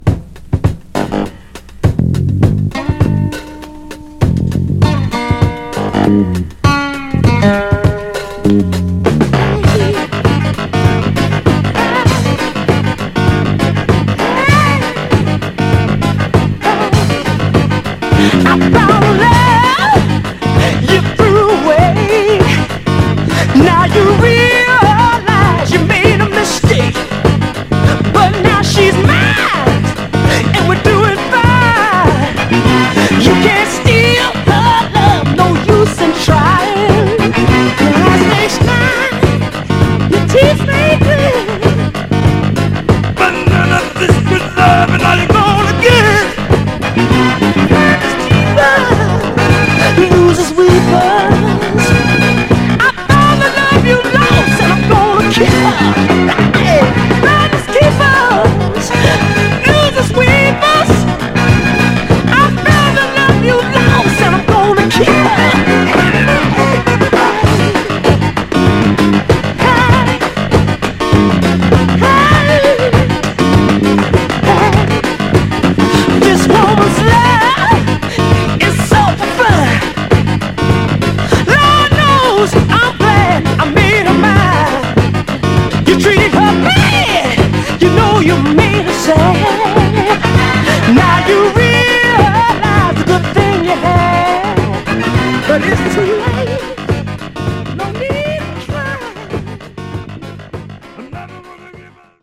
盤は細かいスレ、細かいヘアーラインキズ箇所ありますが、グロスが残っておりプレイ良好です。
※試聴音源は実際にお送りする商品から録音したものです※